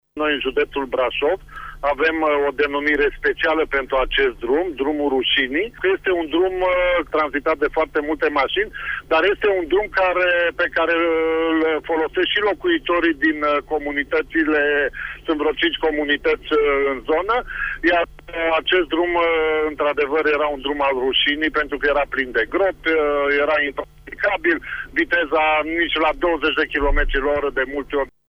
Prefectul de Brașov, Mihai Mohaci, a declarat în emisiunea “Pulsul zilei” de la RTM, că acest drum plin de gropi, impracticabil, pe care nu se putea circula cu mai mult de 20 km/oră, a scăpat, în sfârșit, de titulatura rușinoasă: